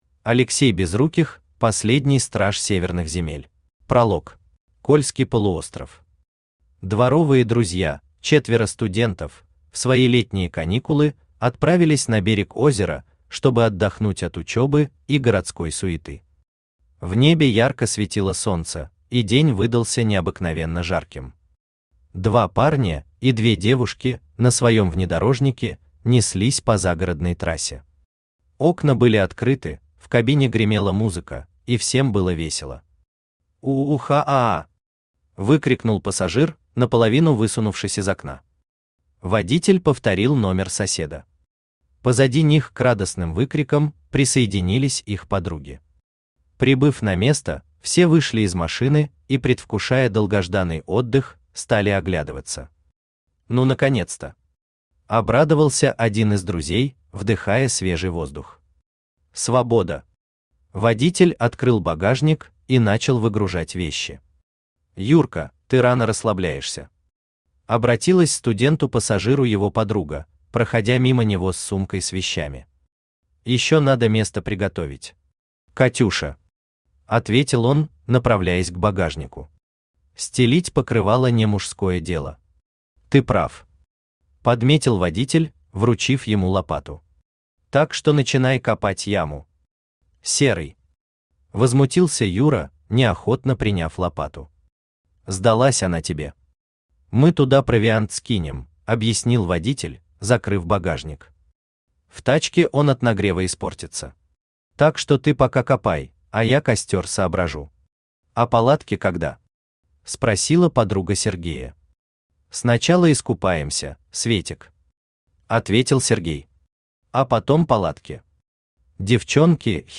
Аудиокнига Последний страж северных земель | Библиотека аудиокниг
Aудиокнига Последний страж северных земель Автор Алексей Олегович Безруких Читает аудиокнигу Авточтец ЛитРес.